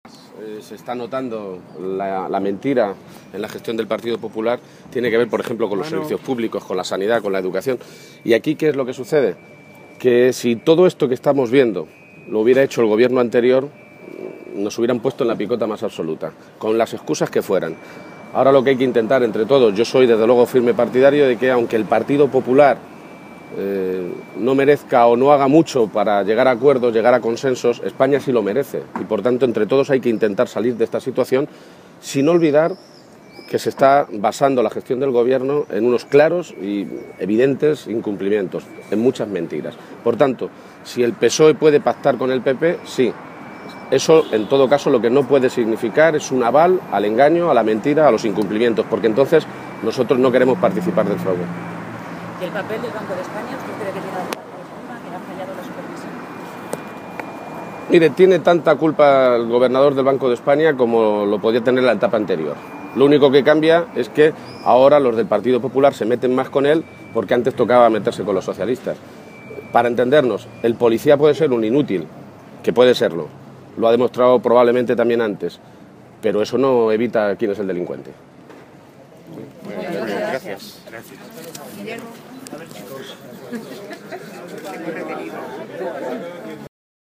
García-Page que realizaba estas declaraciones a su llegada a la reunión del Consejo Territorial del PSOE, quiso dejar claro que ese acuerdo entre ambas formaciones políticas en ningún caso puede significar “avalar los engaños y los incumplimientos del Gobierno; nosotros no queremos participar en ese fraude”.
Cortes de audio de la rueda de prensa